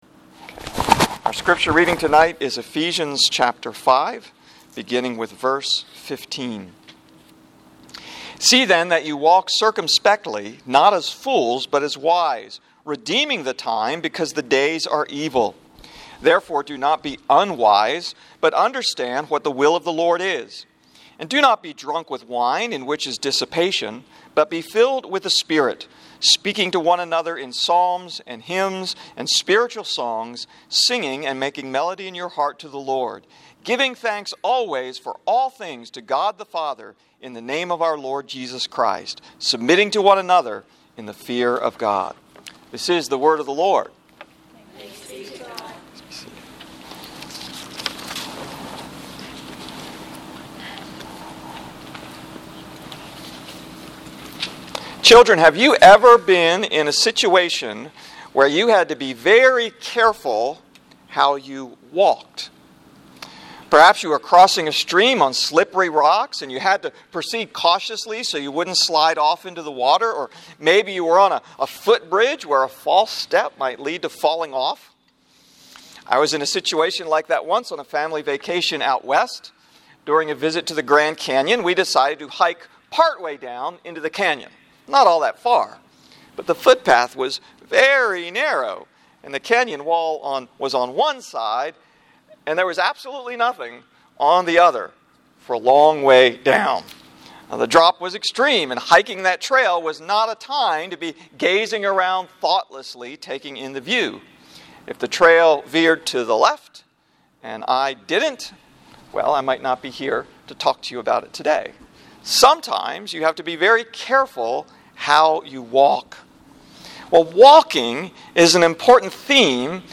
Sunday Evening